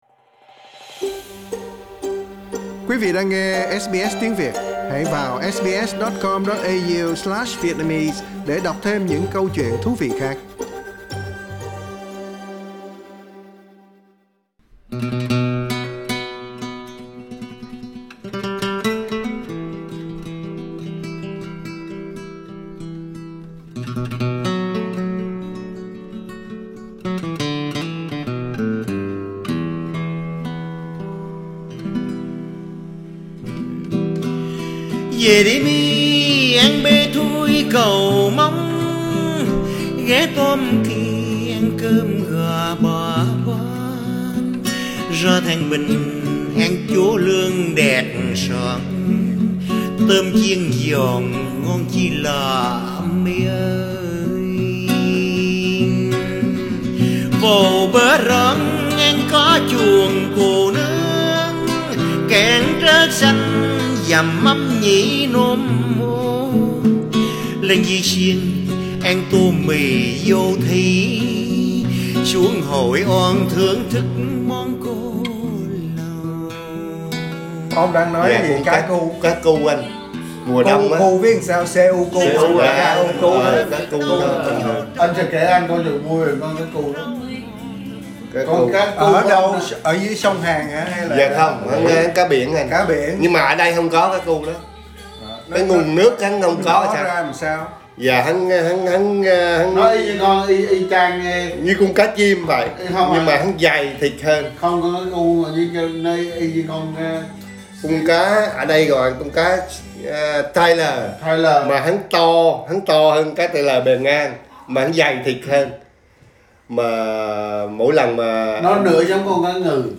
Loại cá bè chỉ có ở miền Trung này còn có thể làm lẩu, hoặc nấu cháo. Chúng ta hãy nghe người địa phương nói về món ăn thú vị này nhé quý vị..